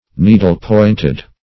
Needle-pointed \Nee"dle-pointed`\, a. Pointed as needles.
needle-pointed.mp3